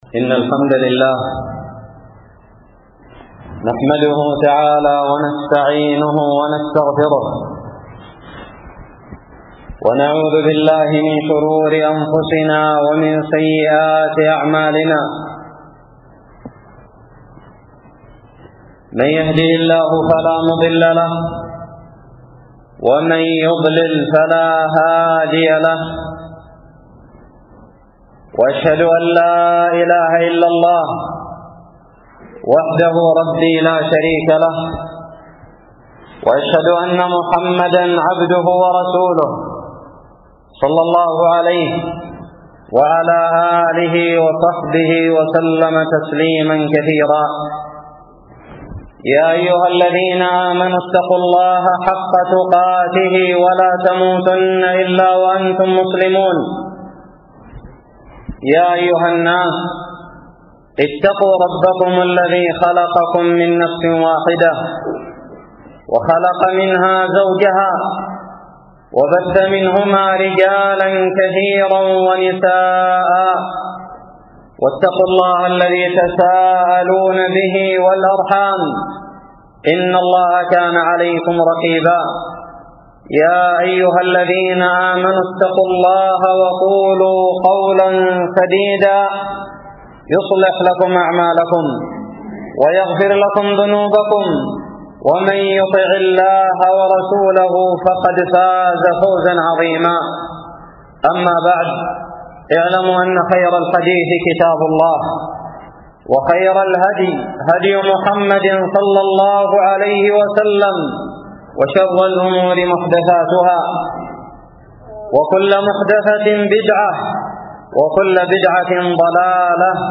خطب الجمعة
ألقيت بدار الحديث السلفية للعلوم الشرعية بالضالع في 29 ذي القعدة 1438هــ